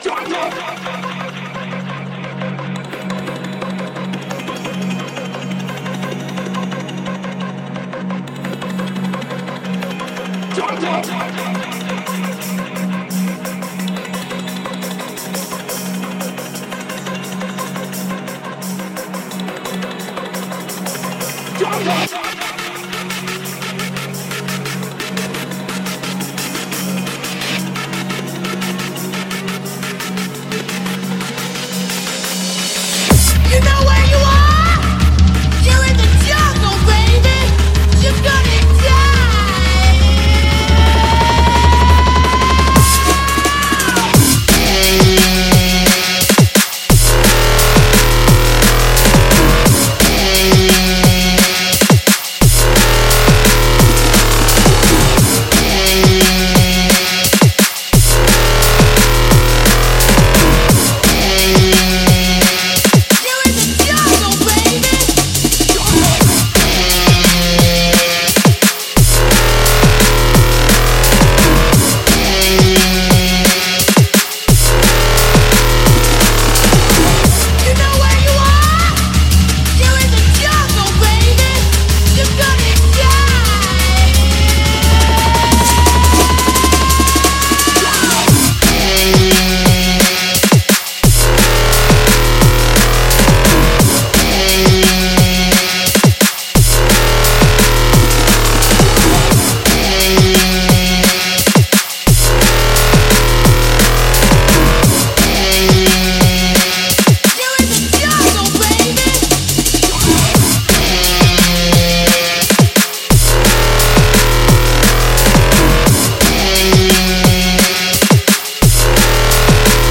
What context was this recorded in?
the file is a reduced audio sample, not the HQ original